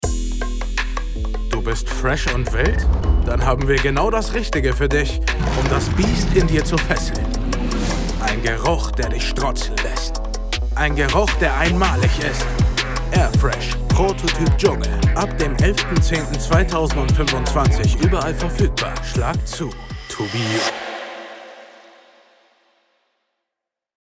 German Voice Over Artist
Sprechprobe: Werbung (Muttersprache):